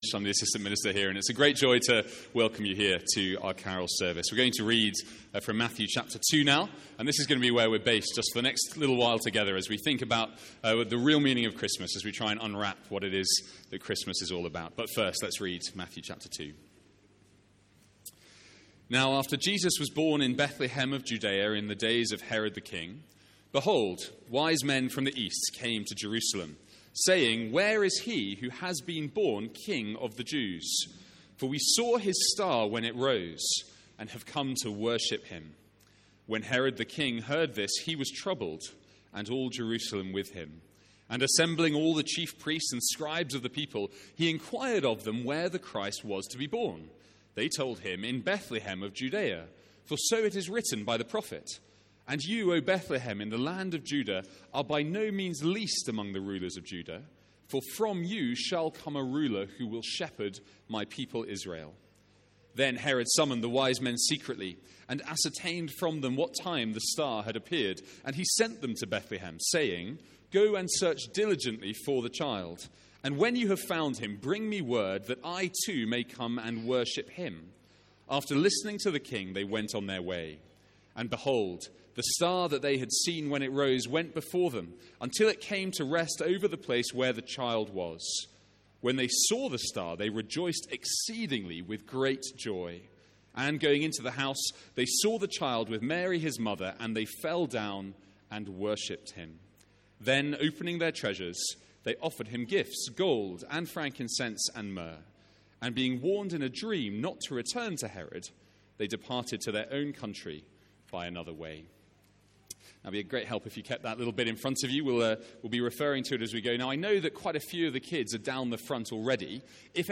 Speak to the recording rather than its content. Family Carol Service 2019 (Matthew 2:1-12) (NB. Children's song and interactive sections cut out). From our Family Carol Service looking at Matthew 2:1-12.